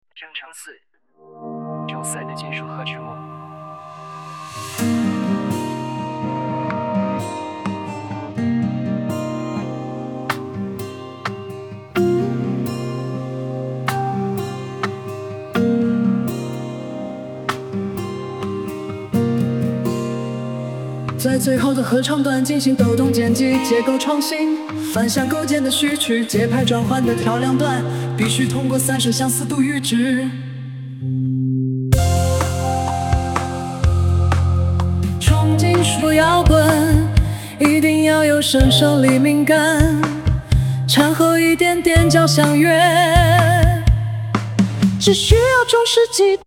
重金属摇滚！一定要有神圣黎明感！掺和一点点交响乐
歌词（人声）只需要中世纪的
人工智能生成式歌曲